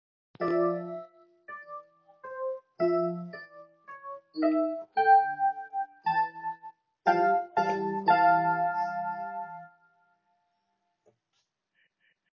I played the Sweezy studios jingle for the very start of the movie with /uploads/default/original/3X/c/0/c076a9aeaced5416b2c1f84e8e98205a037bbc0d.m4a my keyboard Yamaha(my piano)